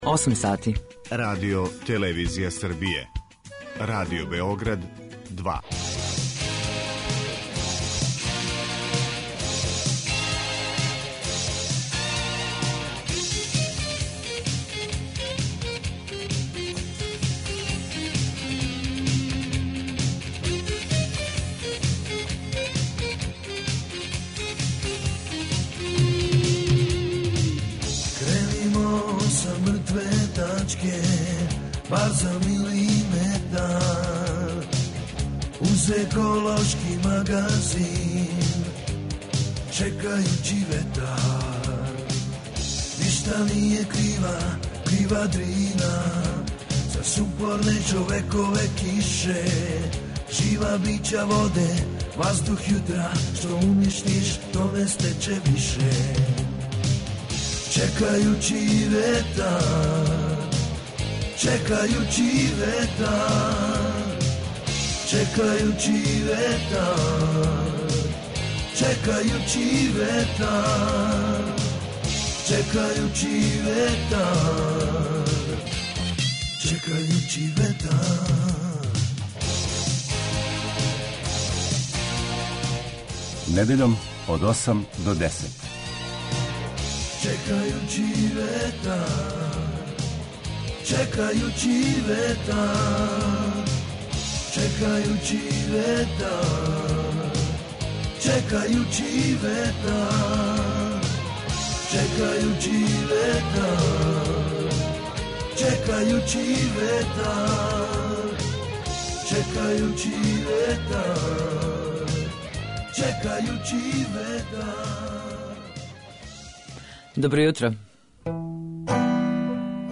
Еколошки магазин
Чућете снимак са свечане доделе Зеленог и Црног листа, која је одржана у Гимназији у Сремским Карловцима.
Гост емисије је епидемиолог др Предраг Кон.